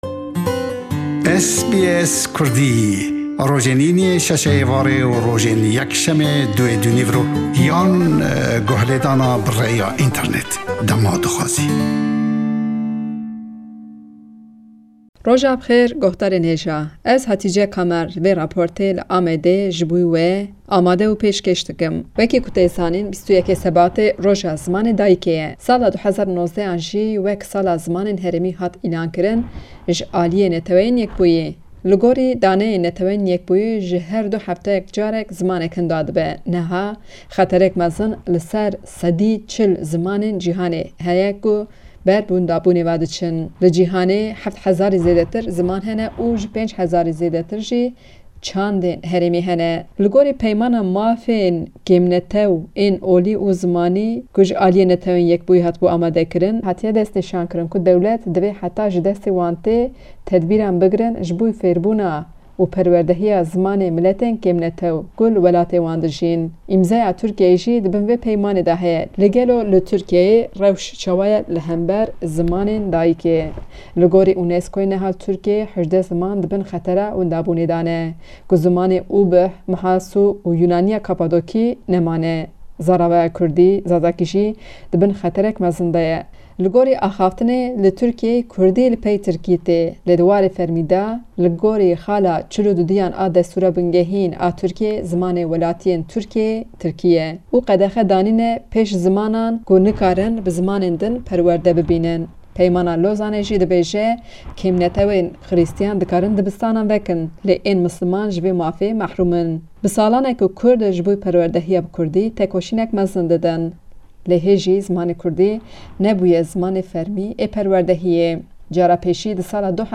Raporta pyamnêra me ji Amedê li ser roja Cihanîya Zinamê Dayikê û rewş û dijwarîyê zimanê Kurdî li Tirjiyê ye. Her sal di 21 Şubatê de ev roja tê pîroz kirin.